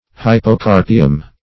Search Result for " hypocarpium" : The Collaborative International Dictionary of English v.0.48: Hypocarp \Hy"po*carp\, Hypocarpium \Hy`po*car"pi*um\, n. [NL. hypocarpium, fr. Gr.